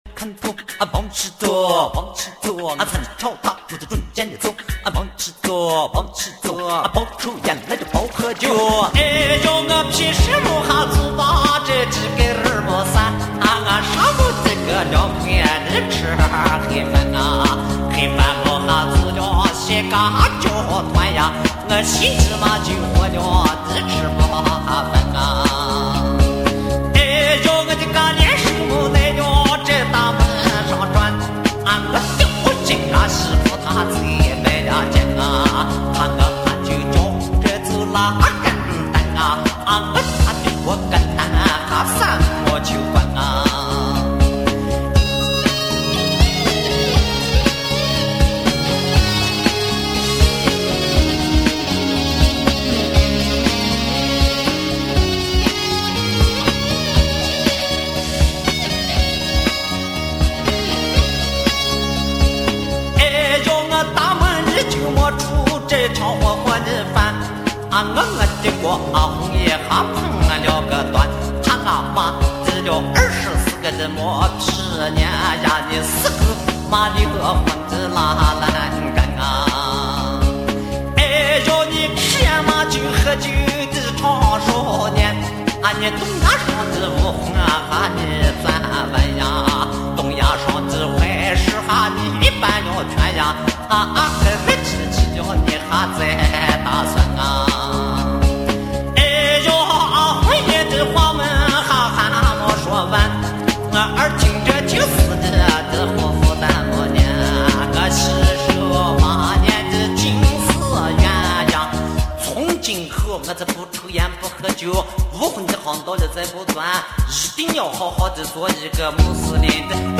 青海花儿